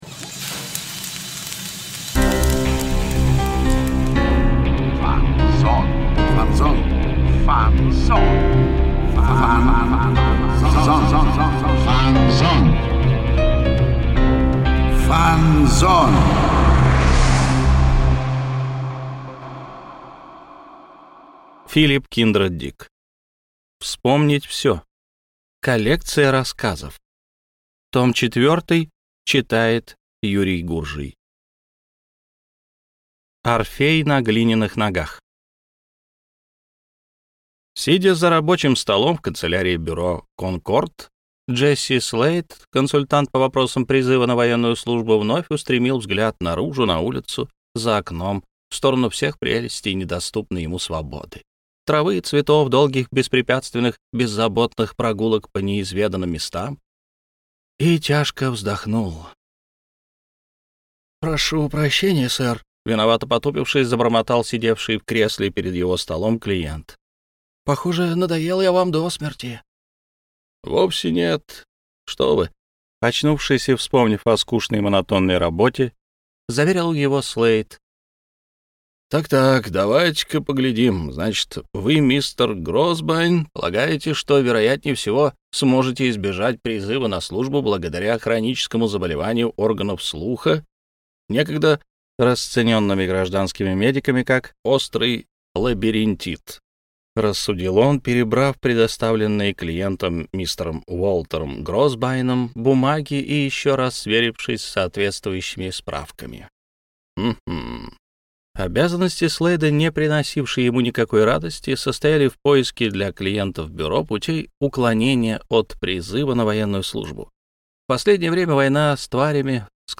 Аудиокнига Вспомнить всё - Скачать книгу, слушать онлайн